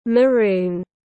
Màu hạt dẻ tiếng anh gọi là maroon, phiên âm tiếng anh đọc là /məˈruːn/.